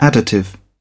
Transcription and pronunciation of the word "additive" in British and American variants.